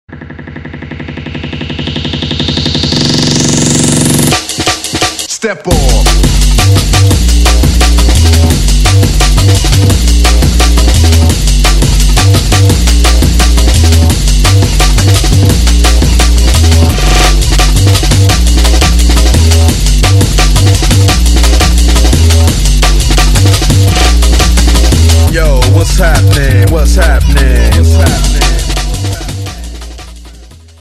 Список файлов рубрики Drum'n'bass mp3